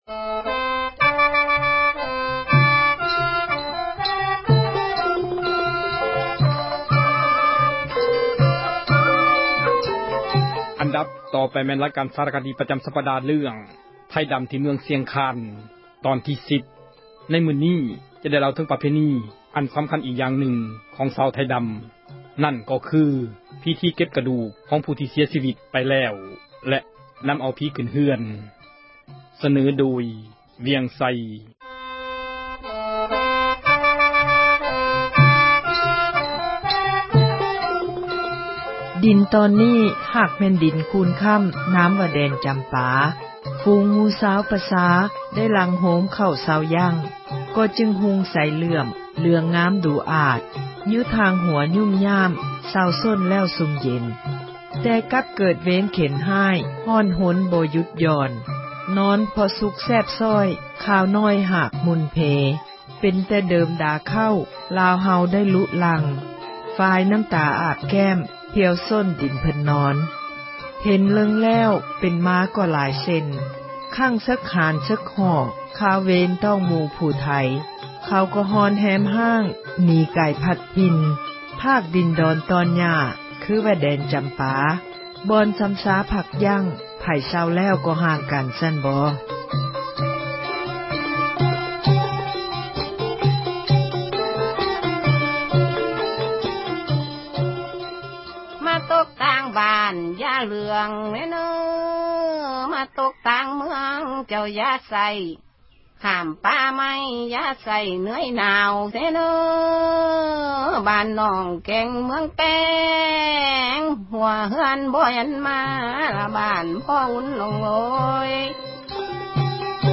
ສາຣະຄະດີ ປະຈຳ ສັປດາ ເຣື້ອງ ”ໄທດຳ ທີ່ເມືອງ ຊຽງຄານ” ຕອນທີ 10 ໃນມື້ນີ້ ຈະໄດ້ເລົ່າເຖິງ ປະເພນີ ອັນສຳຄັນ ອີກຢ່າງນຶ່ງ ຂອງຊາວ ໄທດຳ ນັ້ນກໍຄື ພິທີເກັບ ກະດູກ ຂອງຜູ້ທີ່ ເສັຍຊີວິດ ໄປແລ້ວ ແລະ ນຳເອົາຜີ ຂື້ນເຮືອນ.